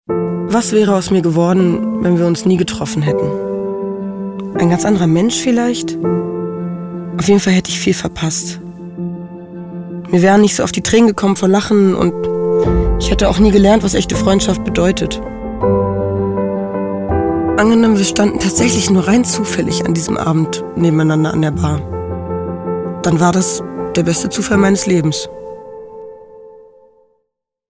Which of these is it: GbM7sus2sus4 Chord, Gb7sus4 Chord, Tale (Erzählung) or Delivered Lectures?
Tale (Erzählung)